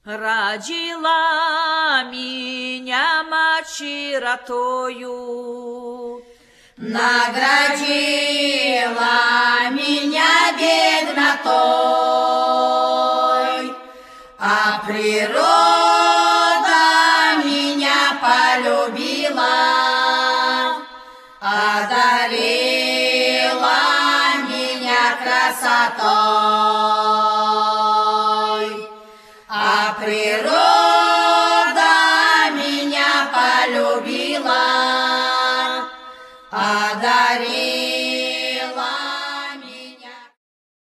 The folk band of Russian Orthodox Old Believers
¶piew vocal